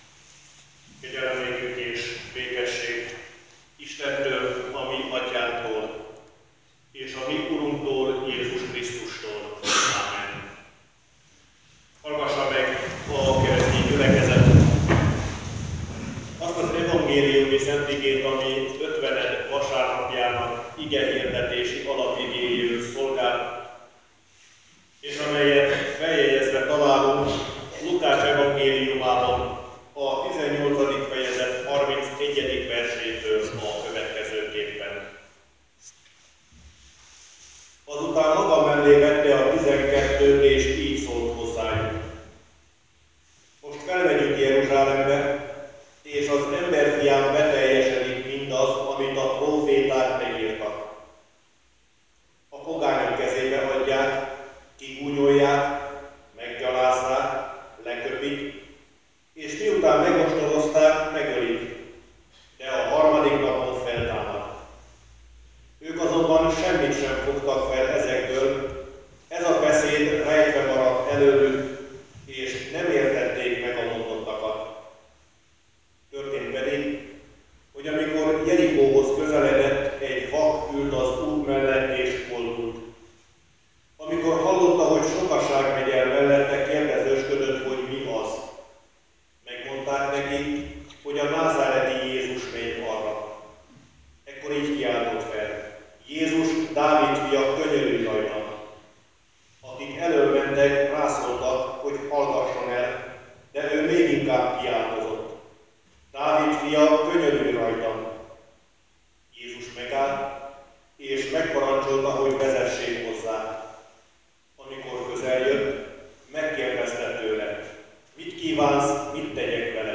Ötvened vasárnap - Légy erős kősziklám (Zsolt 31,3) Technikai probléma miatt elnézést a hangminőségért.